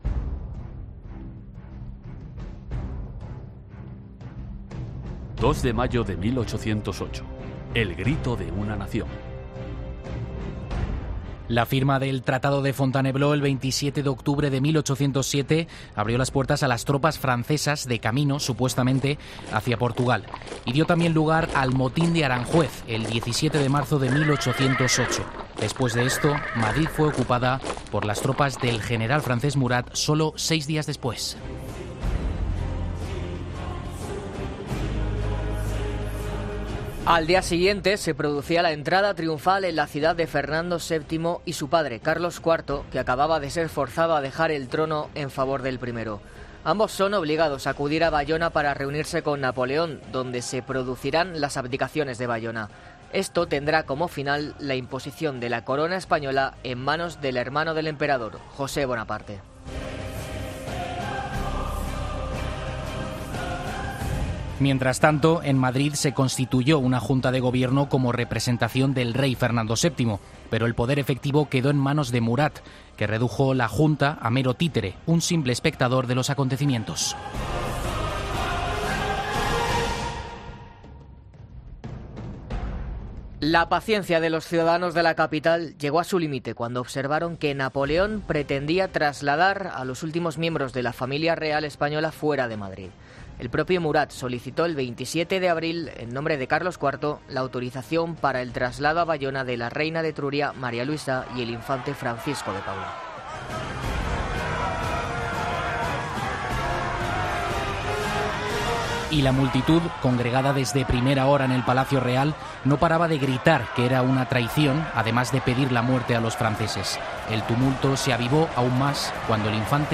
Ficción radiofónica